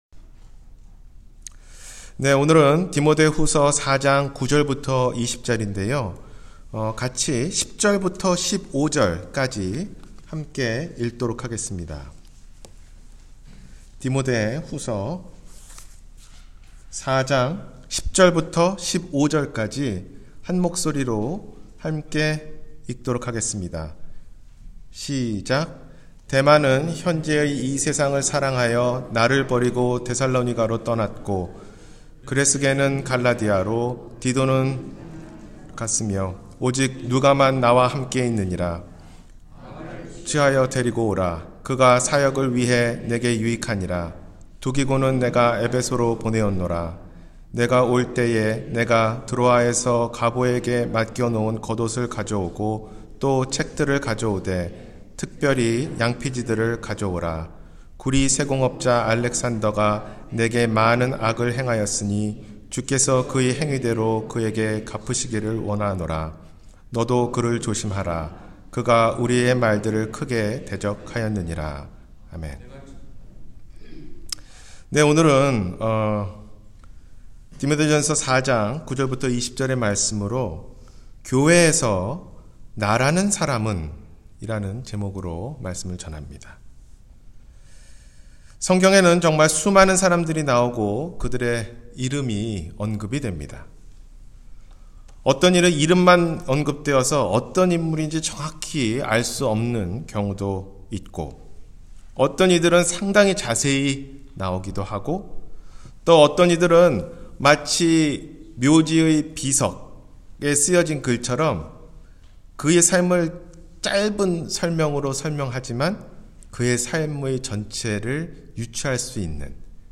교회에서 나라는 사람은?-주일설교